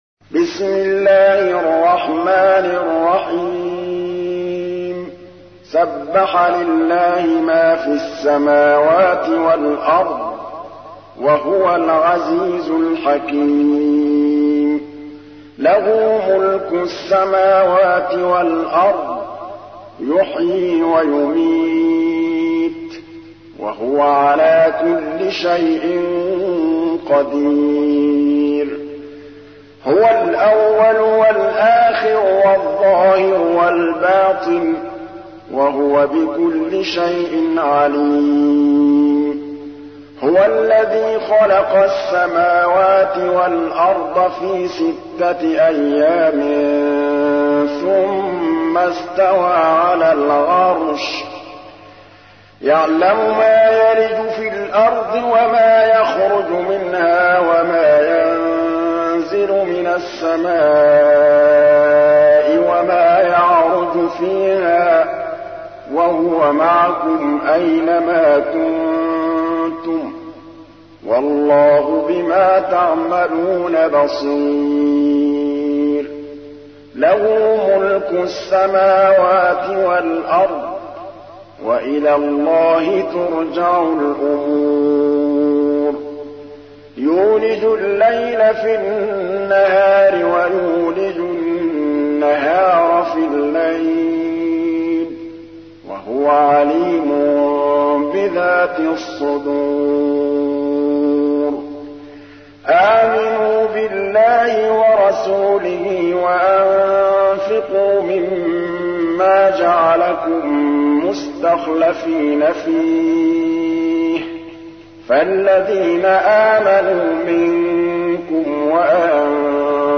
تحميل : 57. سورة الحديد / القارئ محمود الطبلاوي / القرآن الكريم / موقع يا حسين